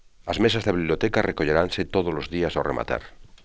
as mésas Da BiBljotÉka rrekoZeráNse tóDolos Días O rrematár.